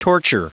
Prononciation du mot torture en anglais (fichier audio)
Prononciation du mot : torture